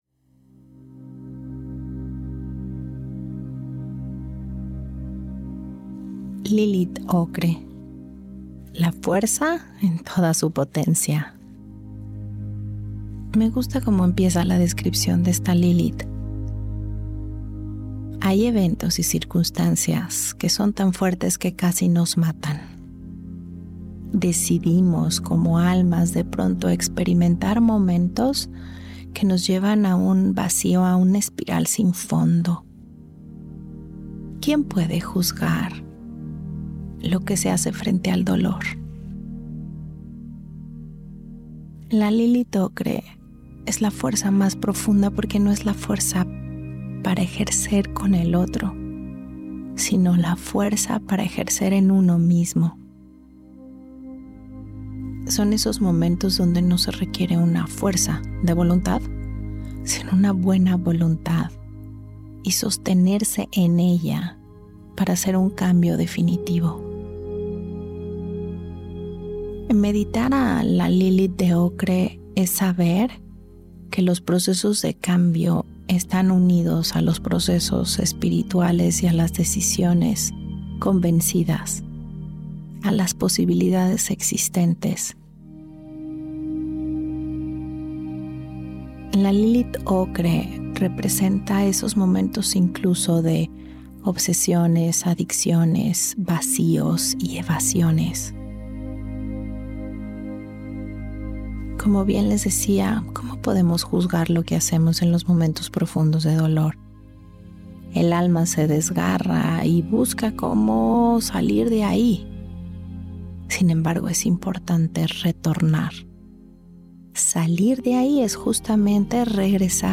Esta meditación nos recuerda que la fortaleza para afrontar cualquier obstáculo nace de la sabiduría obtenida de los momentos más dificiles.